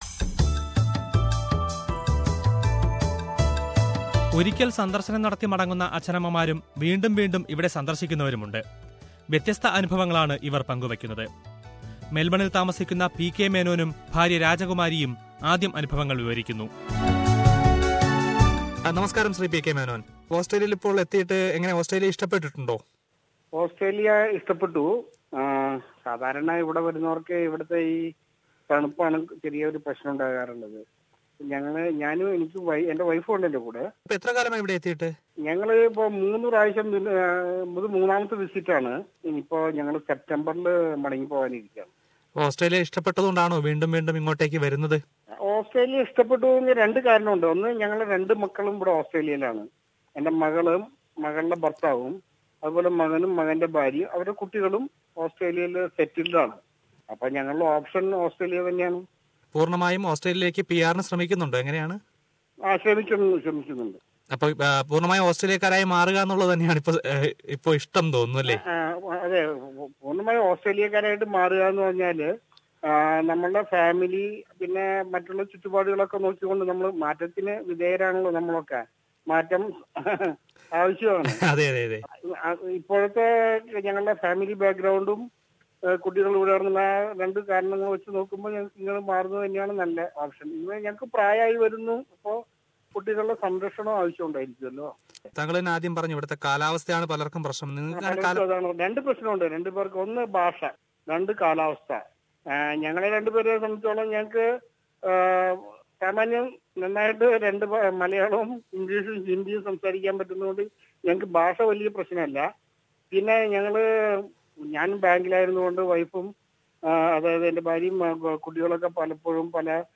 But, are our visiting parents happy here? Let them speak...